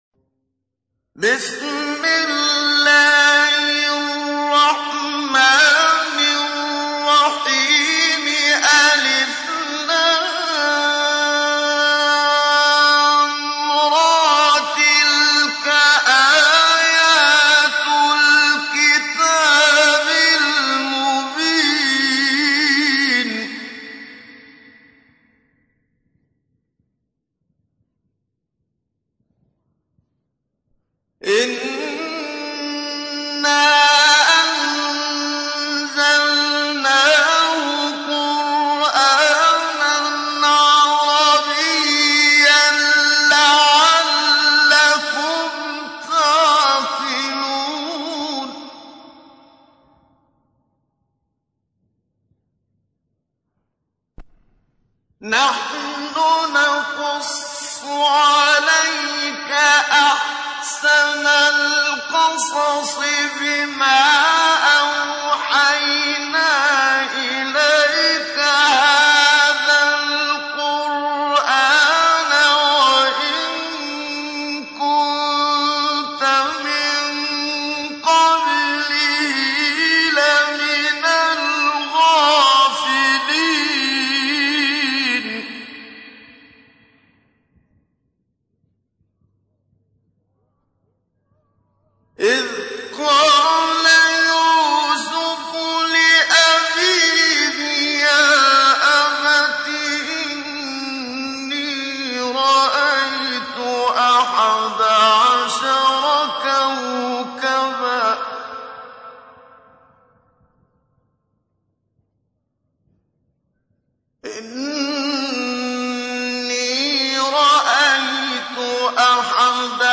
تحميل : 12. سورة يوسف / القارئ محمد صديق المنشاوي / القرآن الكريم / موقع يا حسين